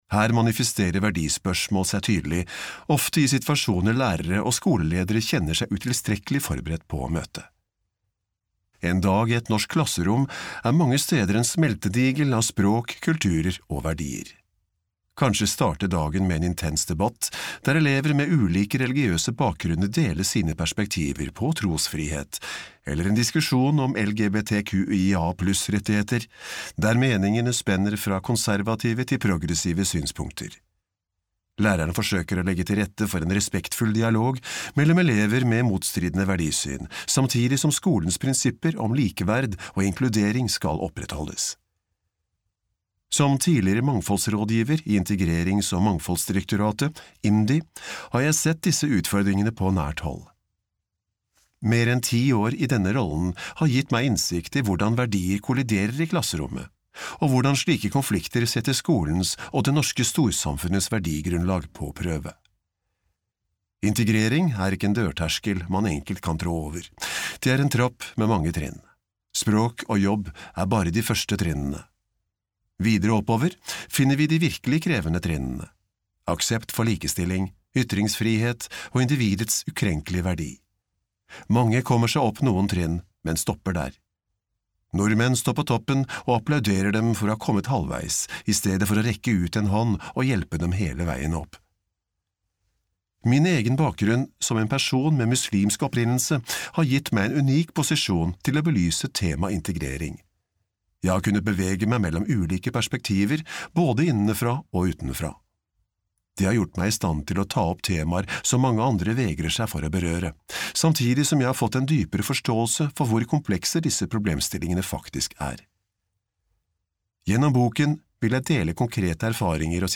Integreringen som ikke gikk seg til (lydbok) av Almir Martin